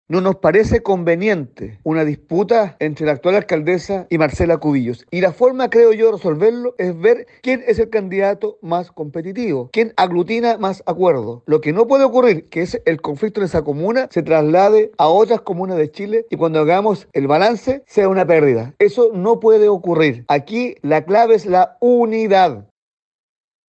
El diputado de la UDI, Henry Leal, espera que la oposición se una para que conflictos, como los que ha generado la irrupción de Marcela Cubillos, no se trasladen a otras comunas.